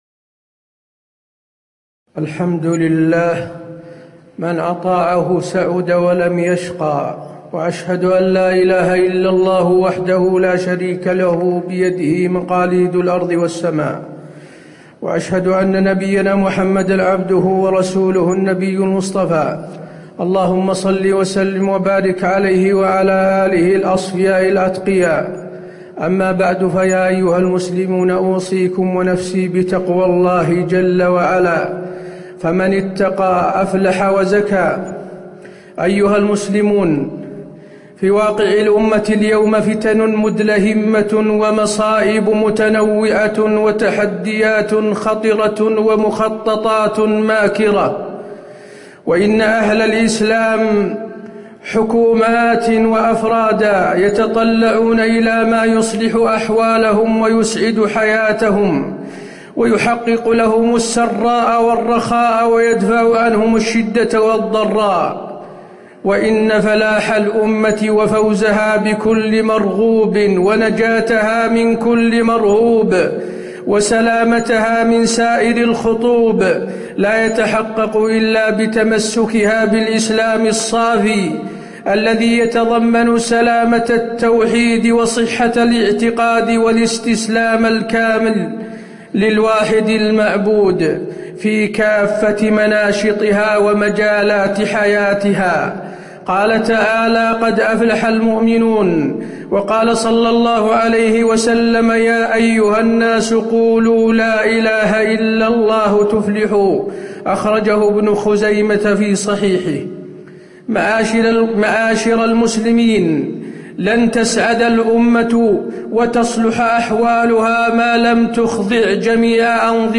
تاريخ النشر ٢٥ صفر ١٤٣٨ هـ المكان: المسجد النبوي الشيخ: فضيلة الشيخ د. حسين بن عبدالعزيز آل الشيخ فضيلة الشيخ د. حسين بن عبدالعزيز آل الشيخ أسباب فلاح الأمة المحمدية